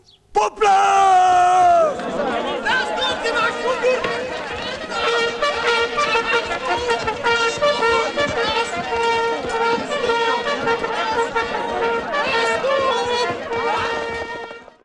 poplach.mp3